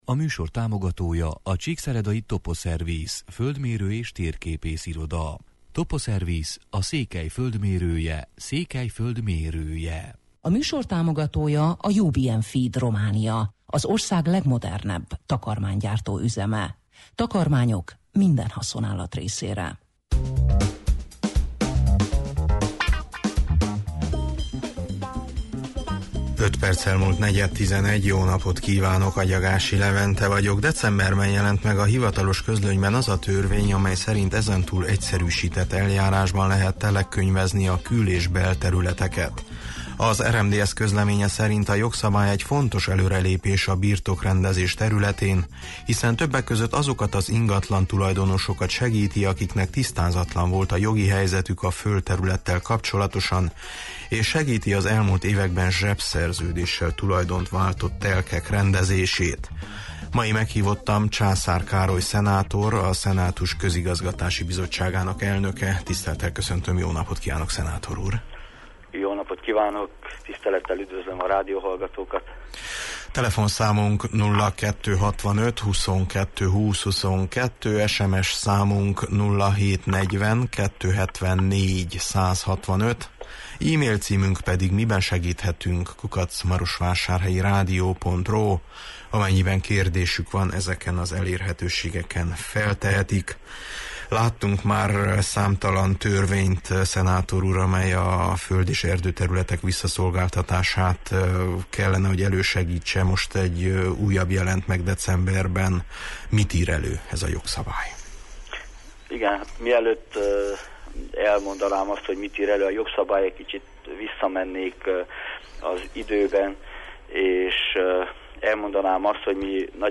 Meghívottam Császár Károly szenátor, a Szenátus közigazgatási bizottságának elnöke: